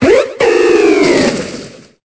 Cri d'Archéduc dans Pokémon Épée et Bouclier.